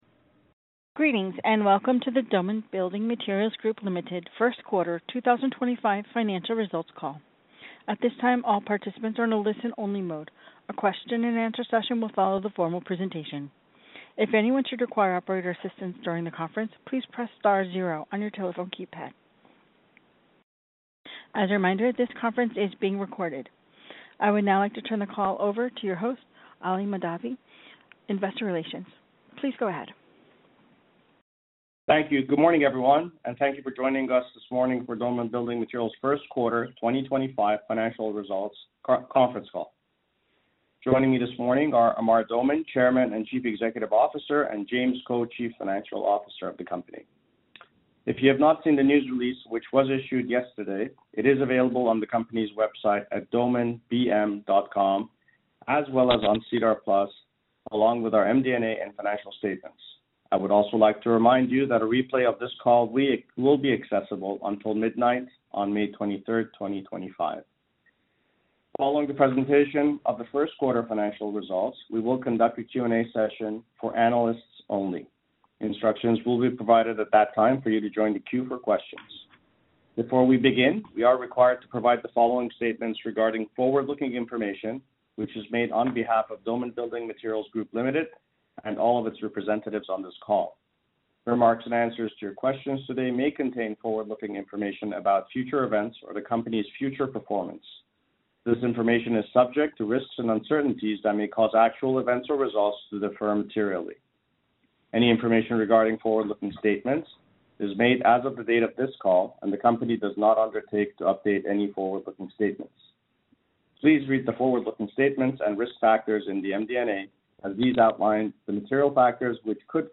Doman_Analyst_Call_Q1_2025.mp3